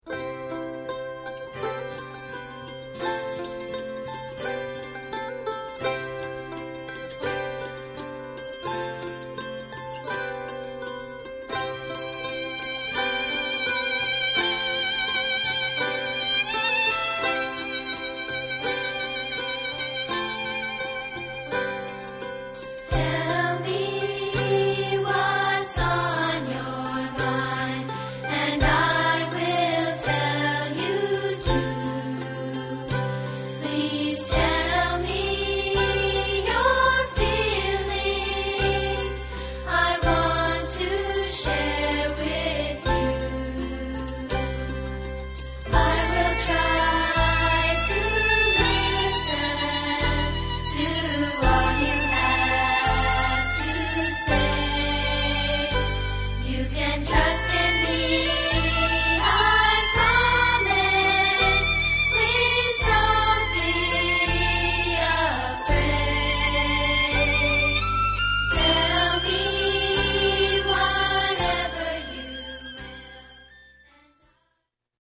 For Young Voices